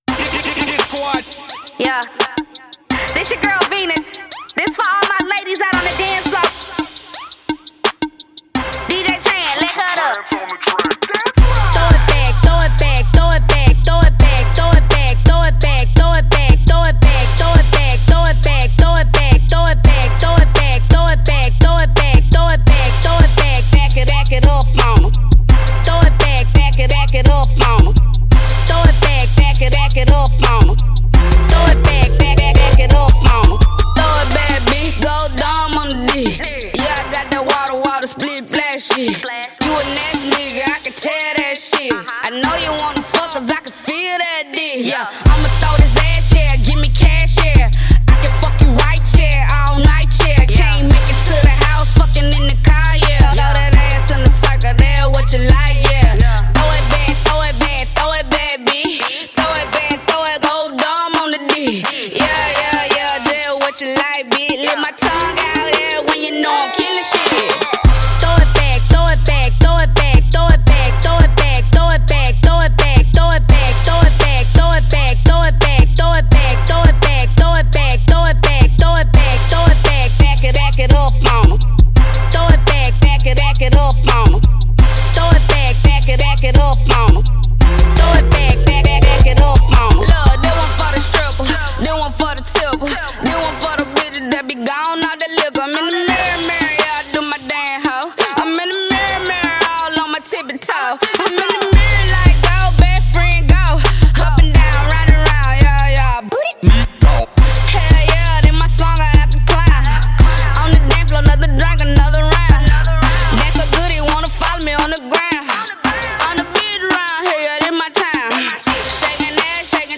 Hiphop
Description: SOUTHERN FEMALE ARTIST